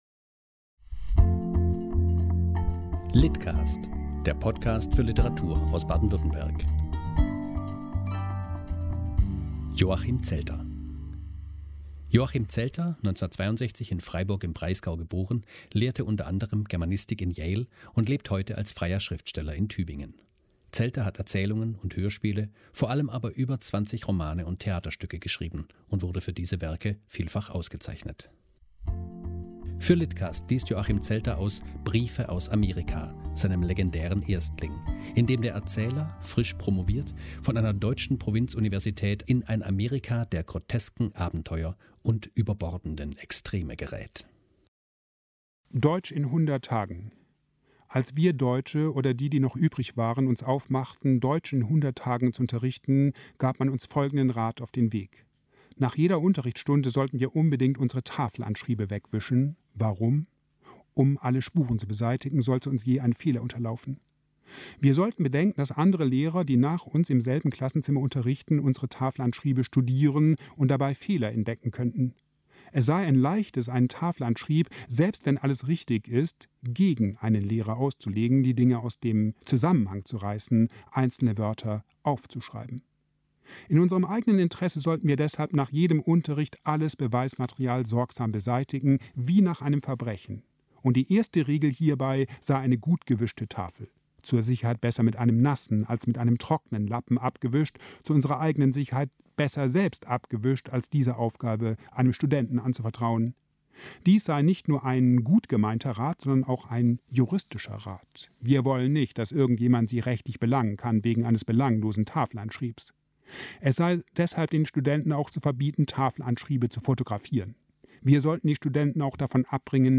Für lit.cast liest Joachim Zelter aus "Briefe aus Amerika" - seinem legendären Erstling, in dem der Erzähler – frisch promoviert – in ein Amerika der grotesken Abenteuer und überbordenden Extreme gerät.